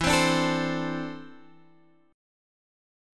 E7sus4#5 chord